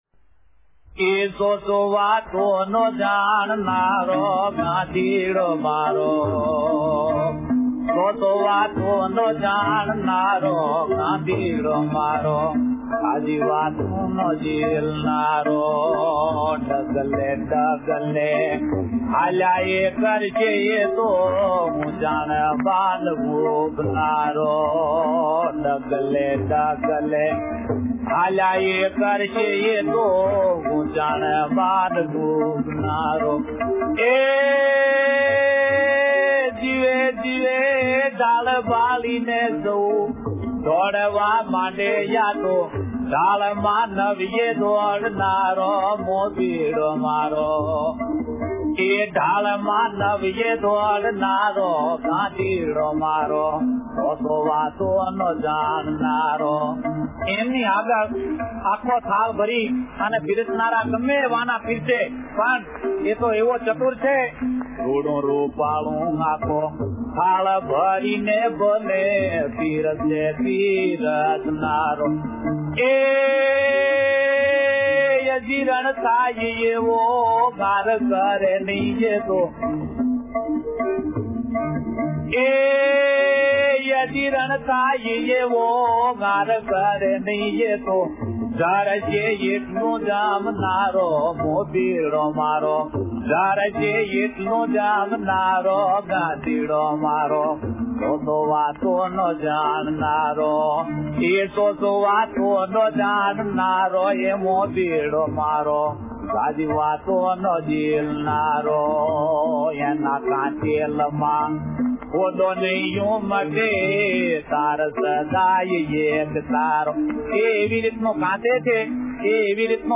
ગાંધીડો મારો - Gandhido Maro - Gujarati Kavita - લોક ગીત (Lok-Geet) - Gujarati World
Source : રચના અને સ્વરઃ દુલા ભાયા ‘કાગ’